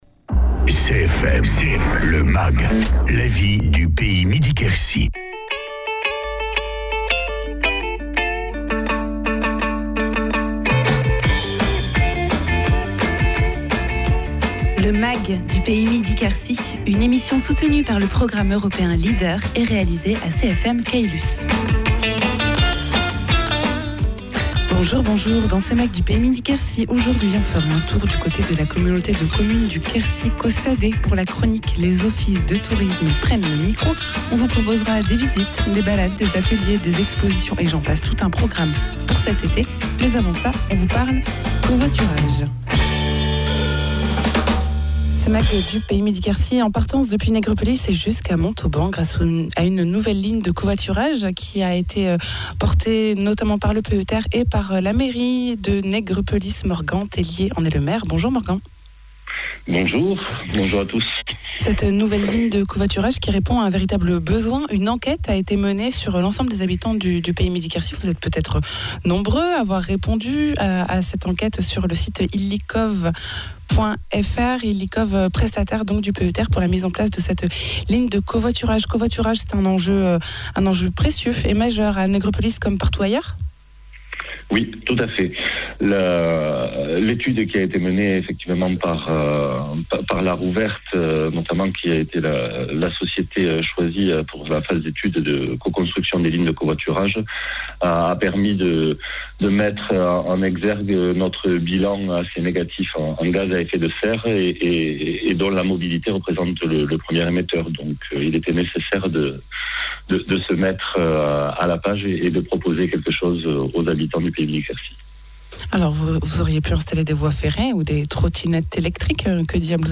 La ligne de covoiturage entre Nègrepelisse et Montauban a été mise en place : témoignage du maire de Nègrepelisse.
Invité(s) : Morgan Tellier, maire de Nègrepelisse